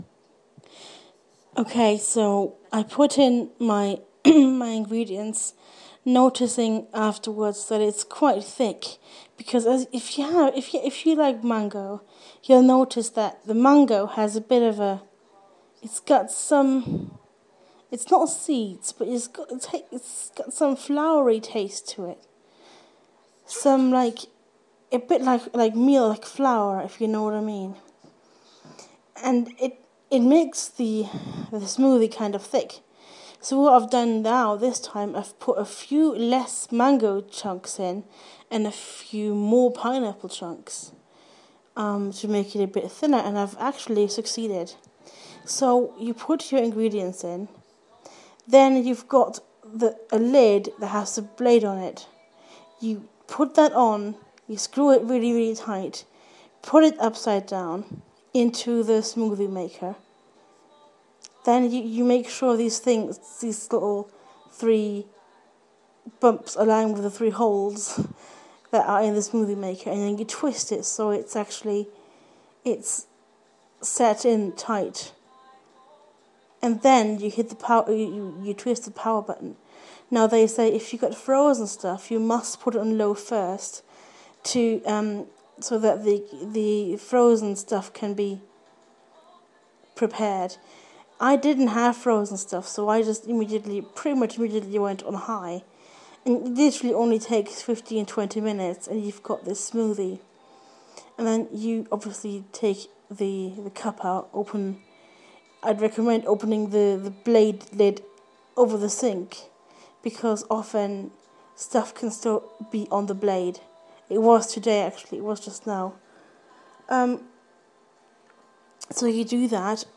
Smoothie maker 2